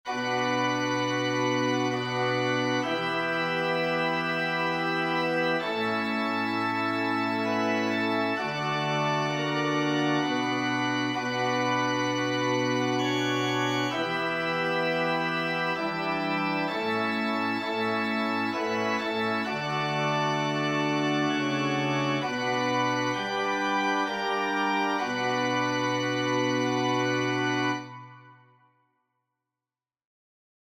Chants de Prière universelle Téléchargé par